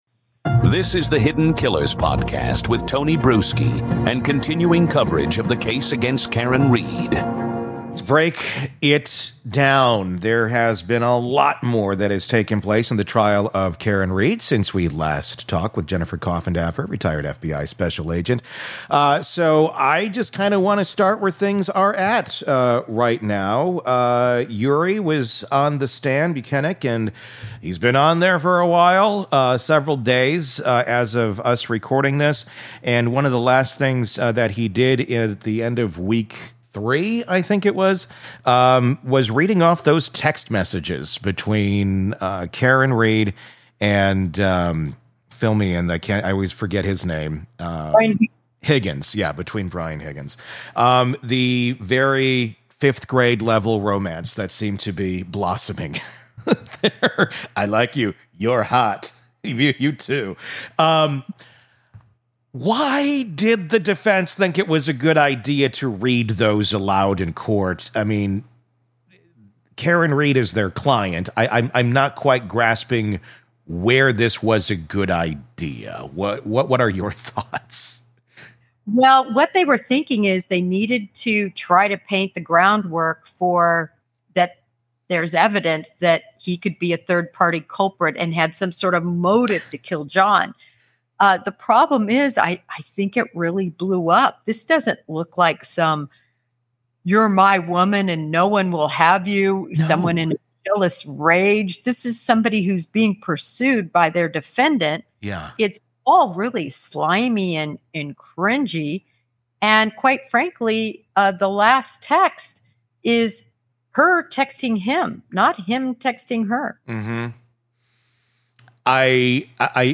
In this in-depth interview